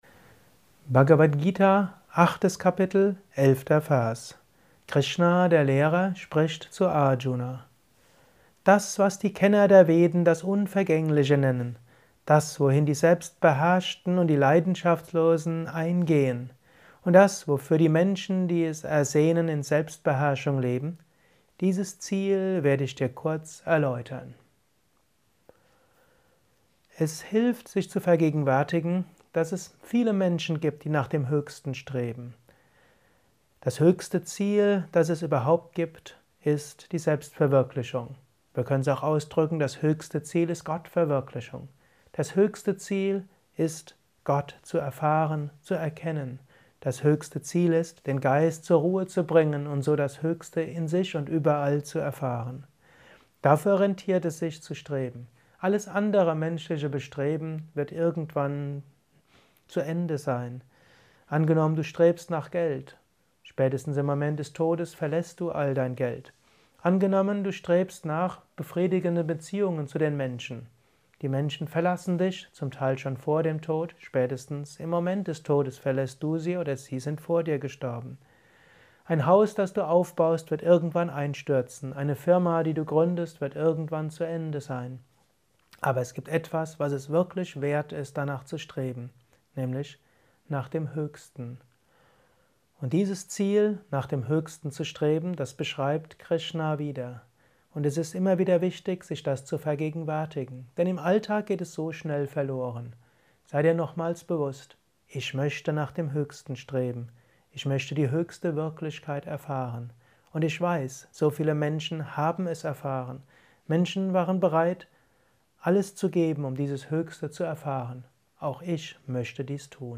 Dies ist ein kurzer Kommentar als